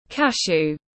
Cashew /ˈkæʃuː/